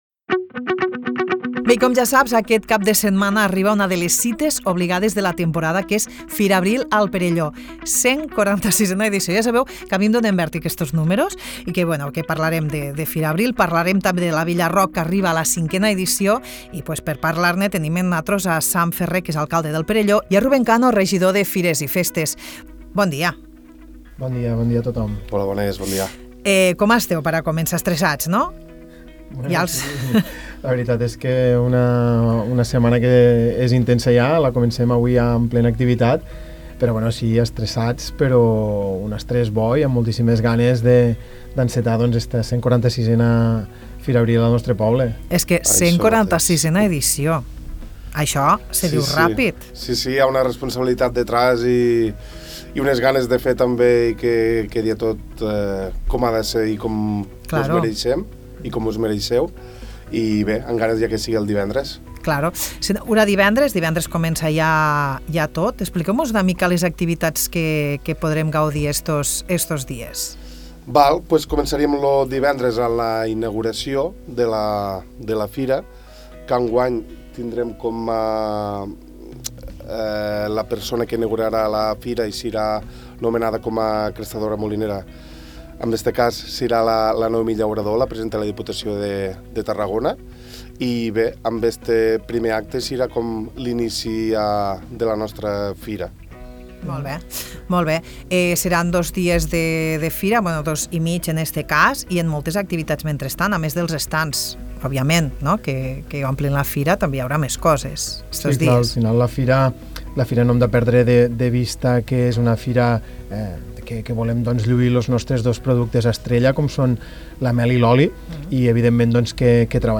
Tal com expliquen Ruben Cano, regidor de fires, i Sam Ferré, alcalde del Perelló, en la programació d’enguany han apostat per novetats com la consolidació de la zona familiar i l’ampliació de l’espai gastronòmic, amb iniciatives com els showcookings ‘Gastroiaies’.